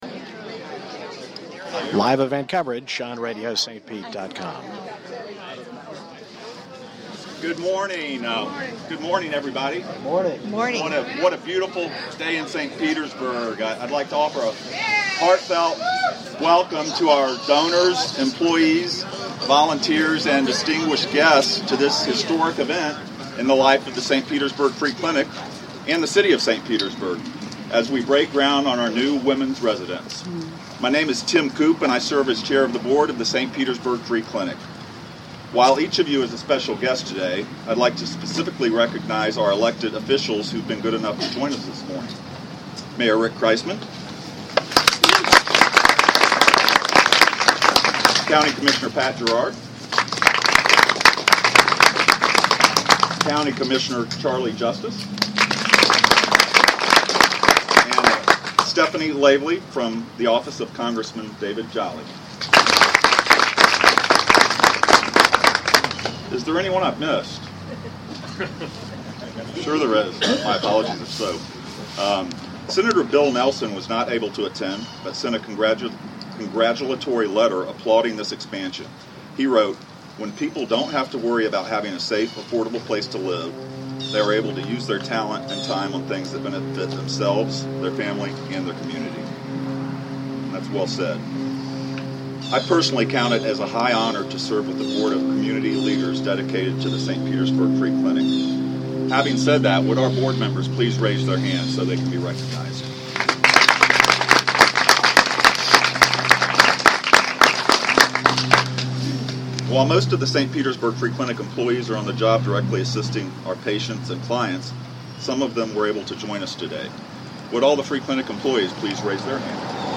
New Women's Residence Groundbreaking Ceremony 1-6-15 St. Petersburg Free Clinic